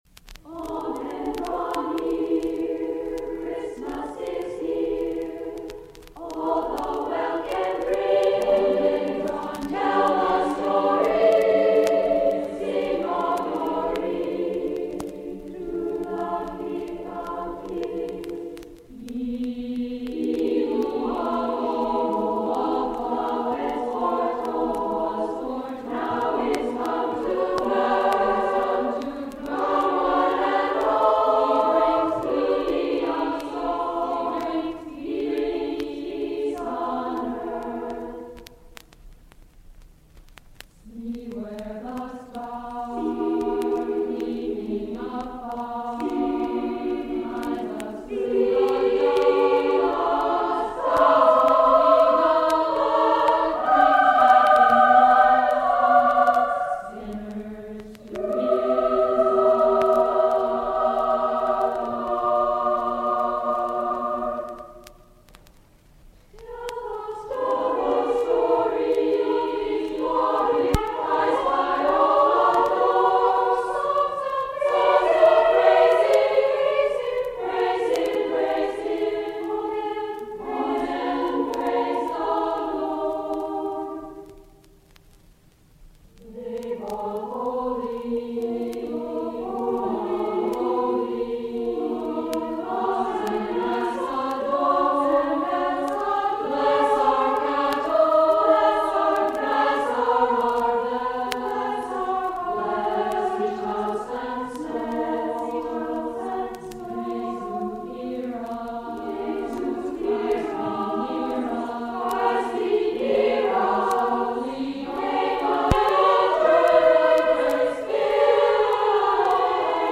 Christmas Concert, 1966